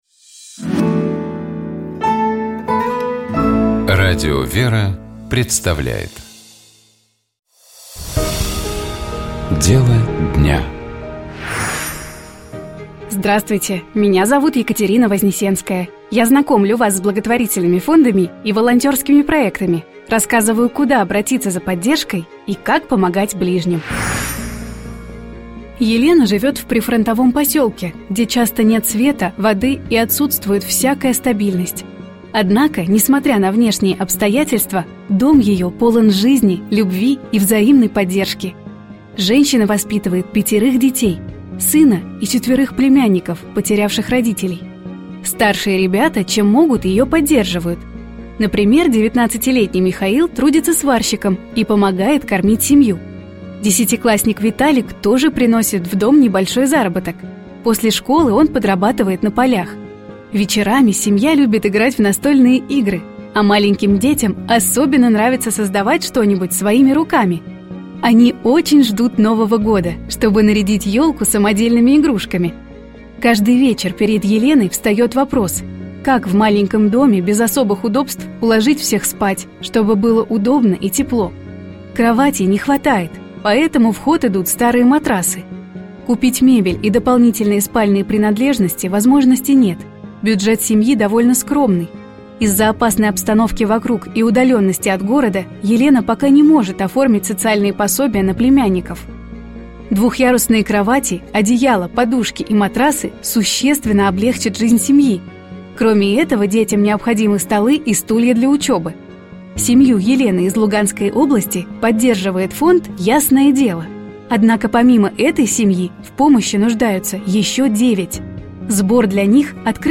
После завершения Божественной литургии в обновленном храме Святейший Патриарх обратился к пастве с Первосвятительским словом, в котором говорил о важности хранения православной веры.